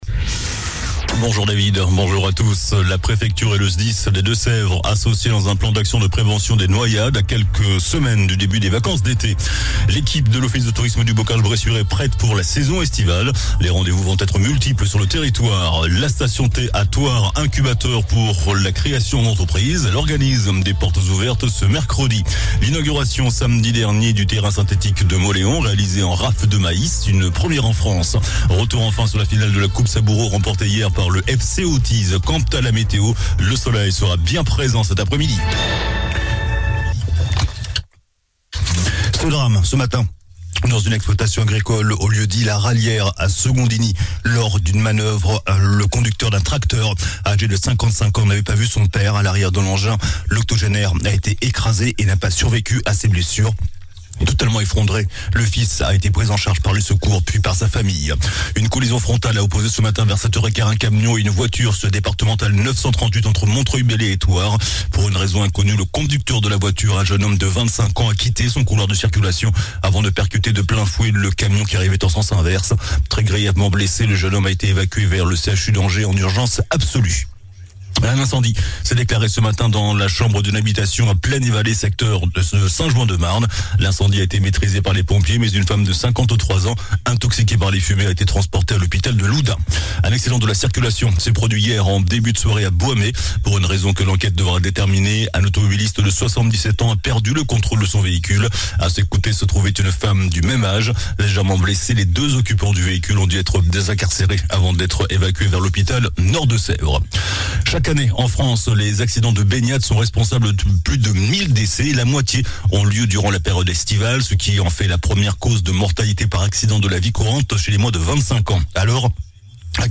JOURNAL DU LUNDI 12 JUIN ( MIDI )